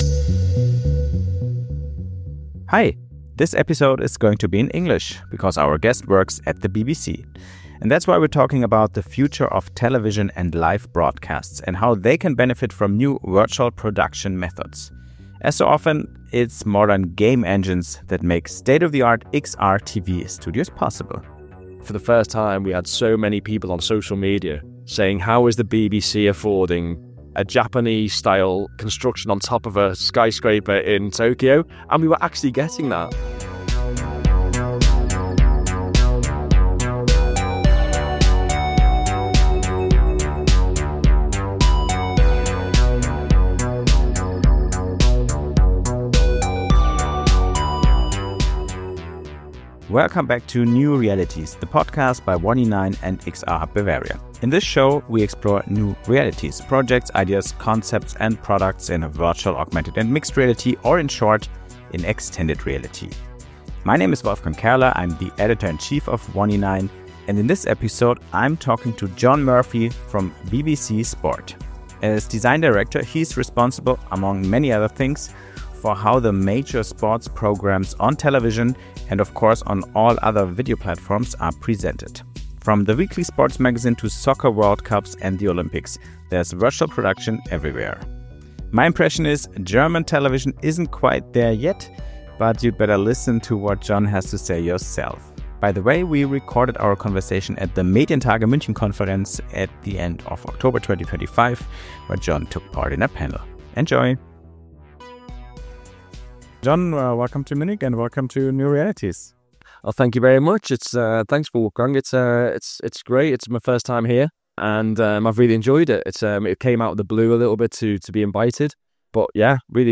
Recorded at Medientage München 2025, this conversation dives deep into how modern game engines, mixed-reality sets, AR graphics, and LED volumes have transformed the BBC’s sports coverage—from Match of the Day to soccer worldcups and Olympic broadcasts.